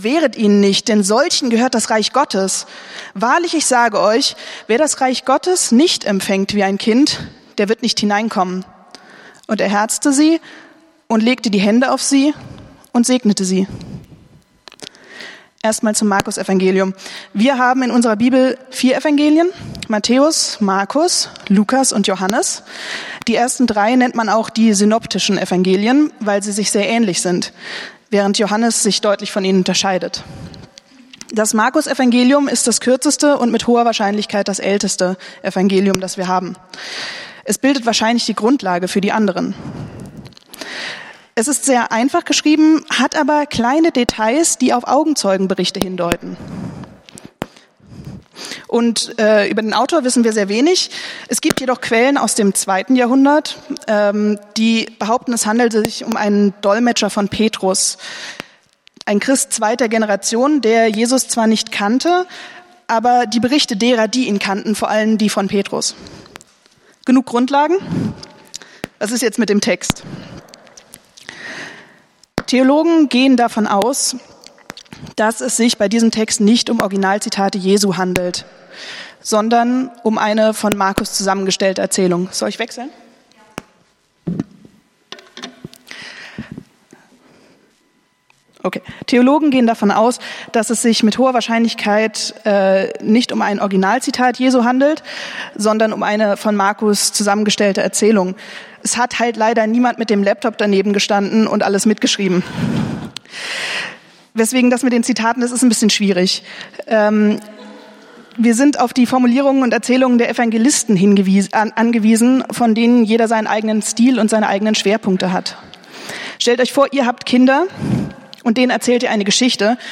Predigt vom 22.10.2023